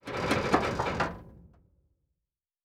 Sci-Fi Sounds
Metal Foley Creak 4.wav